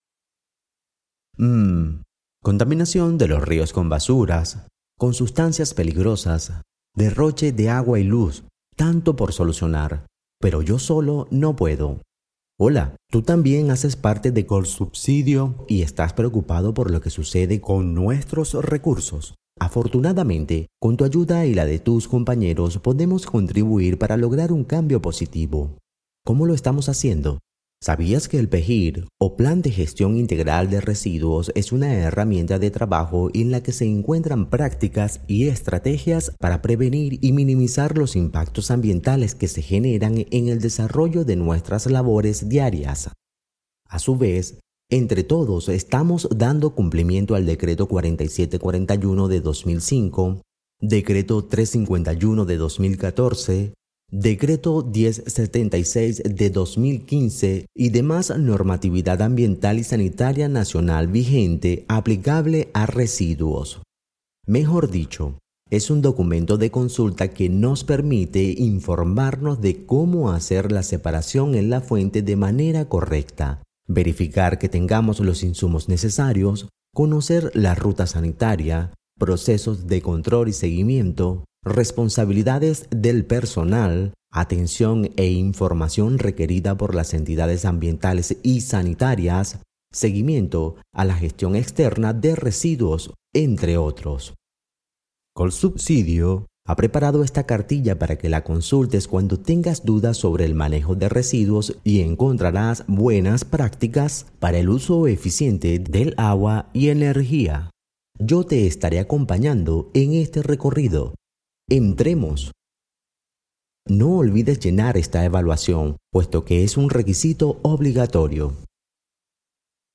Grabacion para vídeo corporativo Tipo tutorial